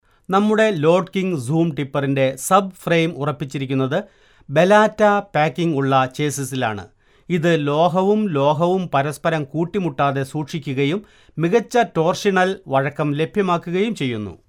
Malayalam Voice Over Artist Samples
Malayalam Voice Over Male Artist 1